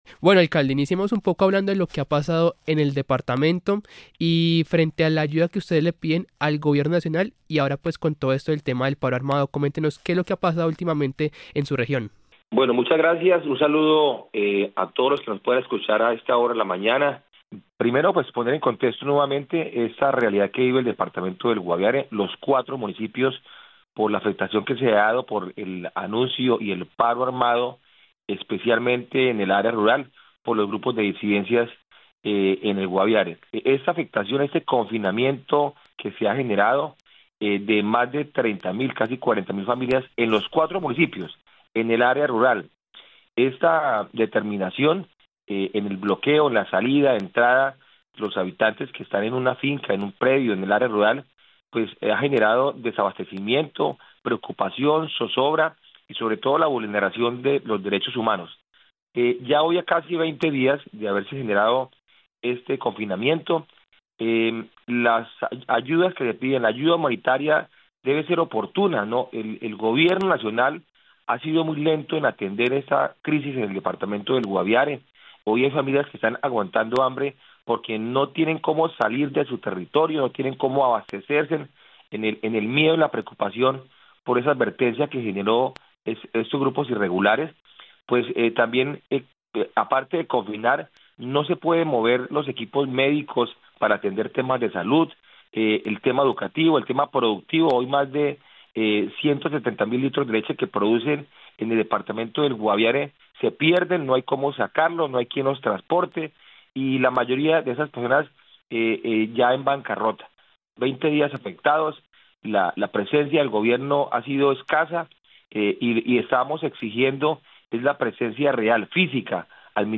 Willy Rodríguez, Alcalde de San José del Guaviare